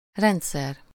Ääntäminen
Synonyymit apparatus complex synthesis structure organization arrangement composition logistics set up machinery set-up Ääntäminen : IPA : /ˈsɪs.təm/ US : IPA : [ˈsɪs.təm] Lyhenteet ja supistumat (laki) Sys.